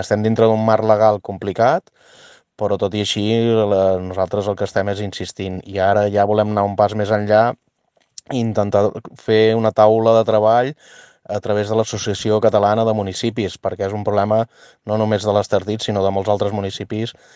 Per la seva banda, el president de l’EMD, Francesc Ferrer, apel·la a fer “un front comú entre els municipis catalans que es veuen afectats per aquesta problemàtica per eradicar-la”: